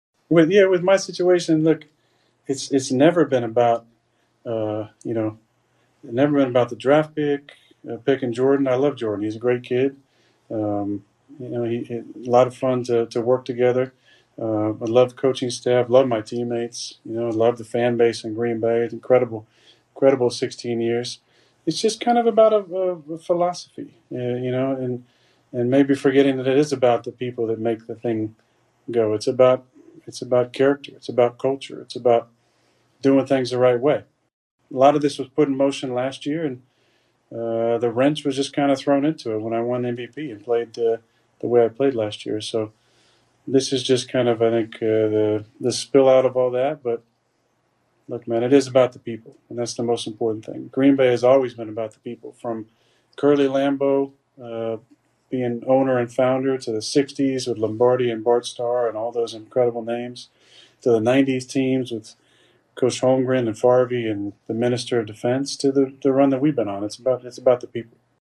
Rodgers was a guest of Kenny Mayne who was hosting his final ESPN SportsCenter broadcast.